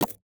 UIMvmt_Menu_Slide_Next_Page_Close 04.wav